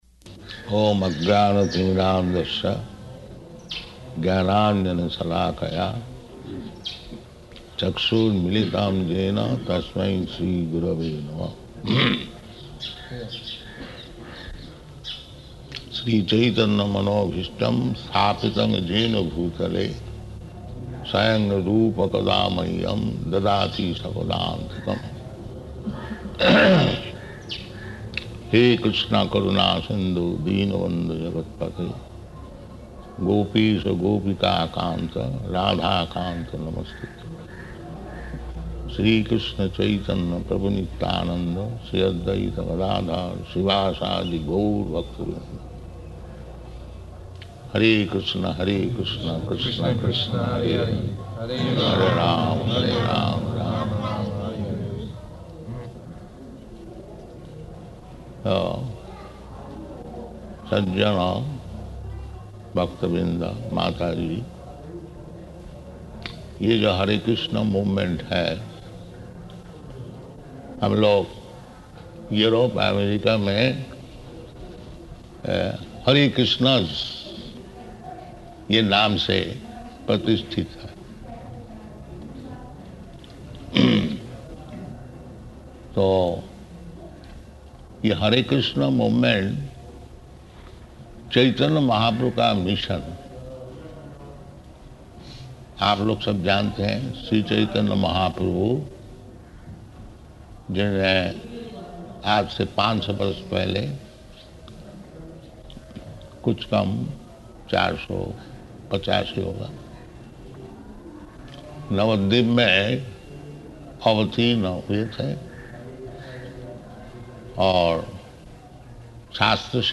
Lecture in Hindi
Type: Lectures and Addresses
Location: Vṛndāvana